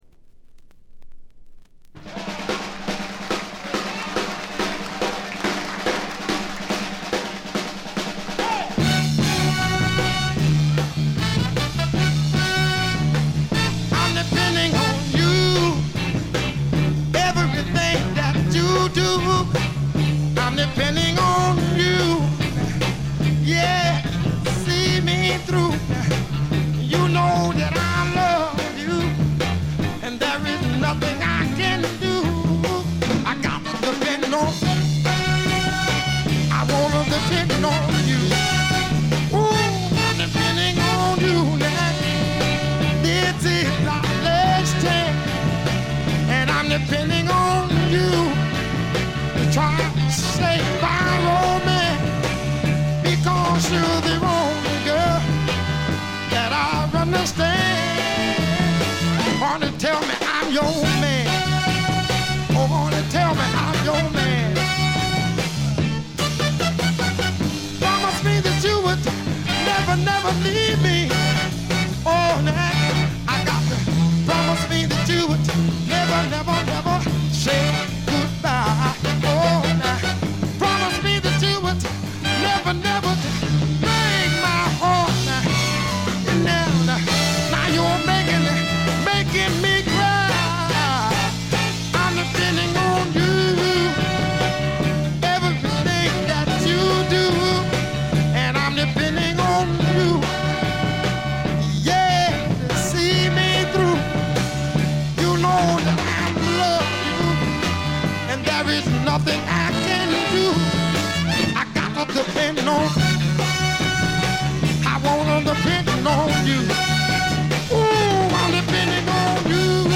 散発的なプツ音が少々。
試聴曲は現品からの取り込み音源です。